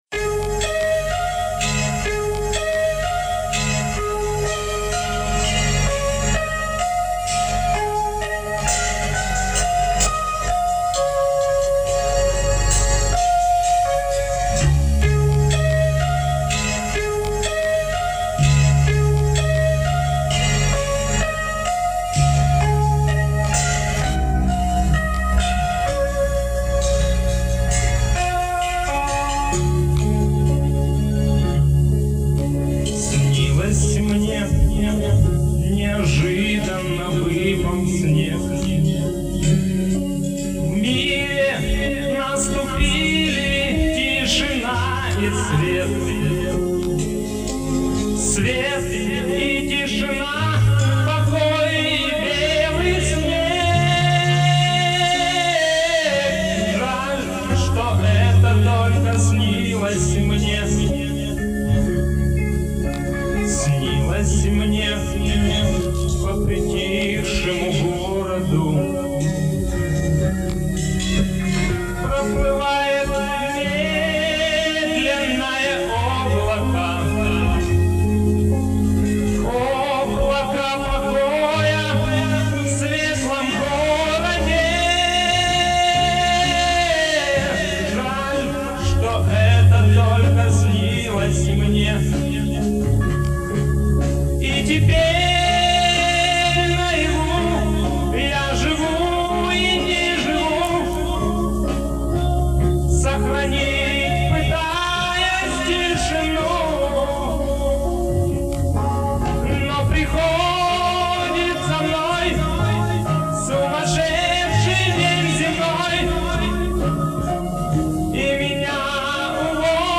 Но многие блюстители морали никак этого не возьмут в толк и начинают гундеть о высоких материях и падению морали поднимаясь в своем морализаторстве "высоко за хмары-аж на колокольню".А в такие вещи лучше бы не лезть.Ну а поскольку дело коснулось ностальгии-послушайте мою банду 30летней давности(запись с танцплощадки на бытовой магнитофончик -чудом сохранившаяся у благодарных поклонников)